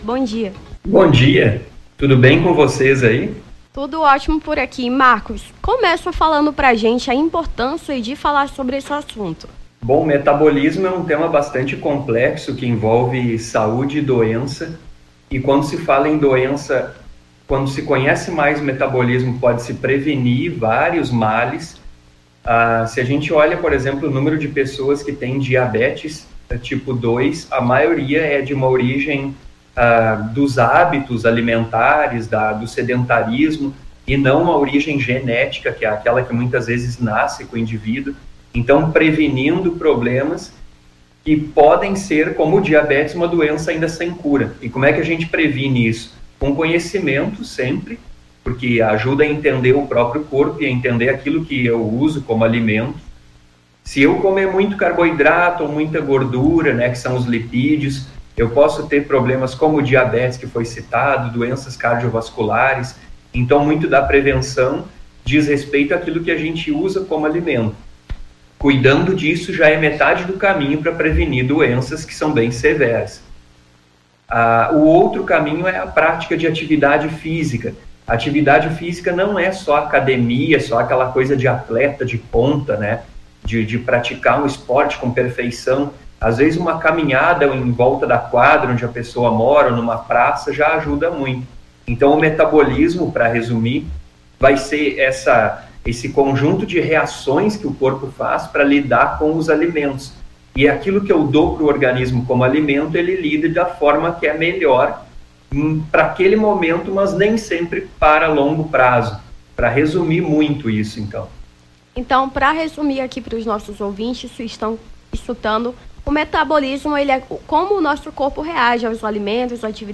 Nome do Artista - CENSURA - ENTREVISTA (COMO O SEU METABOLISMO FUNCIONA) 11-09-23.mp3